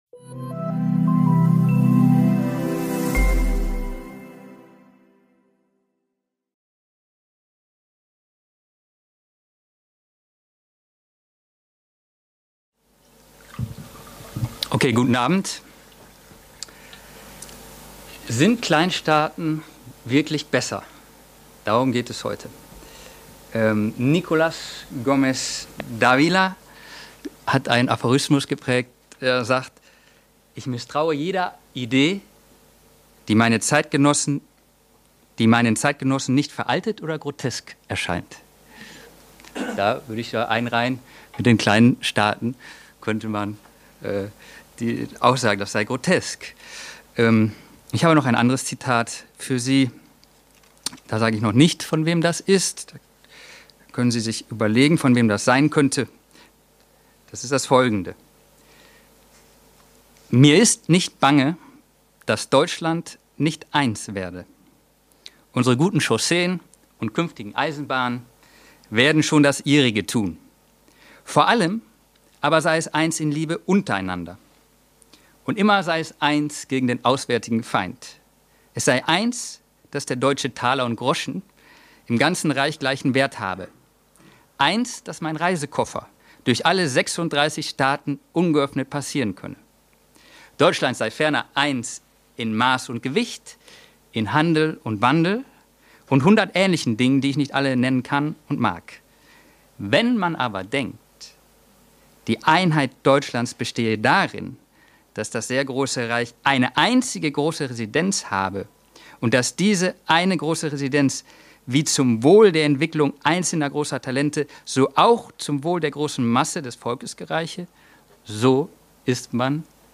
vor 60 Zuhörern in der Bibliothek des Konservatismus